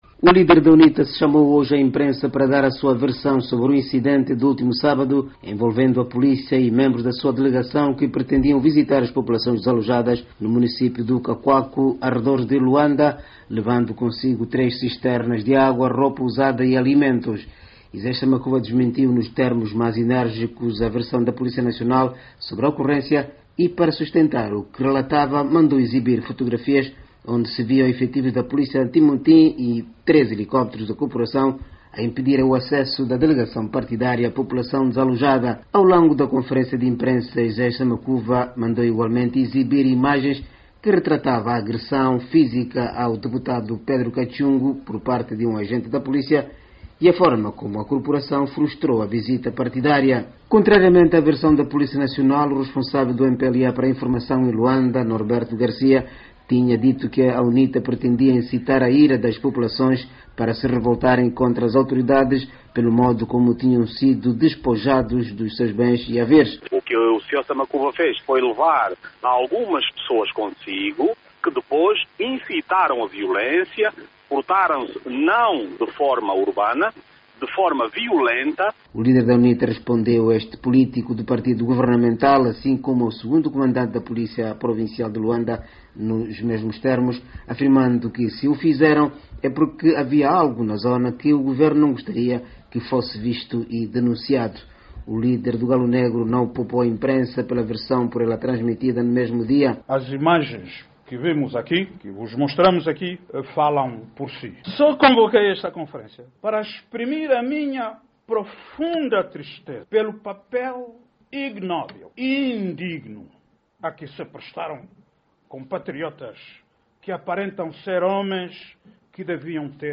Conferência de imprensa de Samakuva